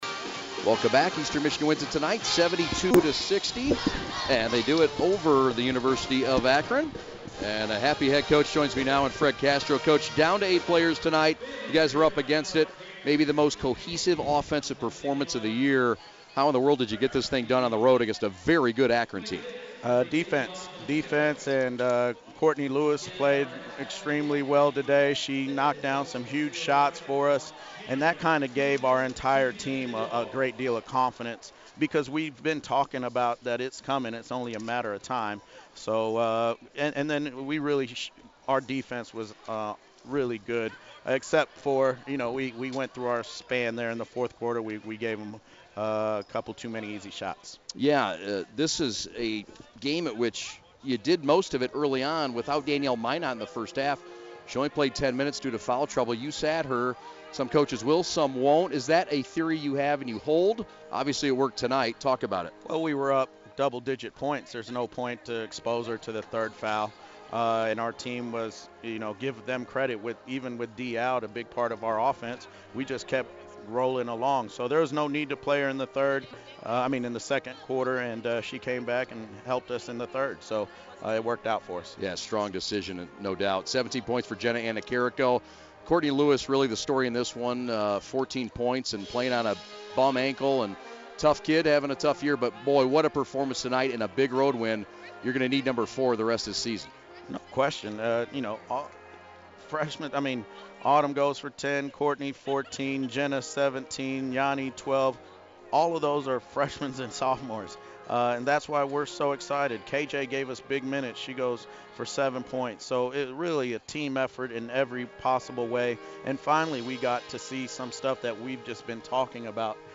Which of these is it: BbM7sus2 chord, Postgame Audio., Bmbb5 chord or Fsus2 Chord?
Postgame Audio.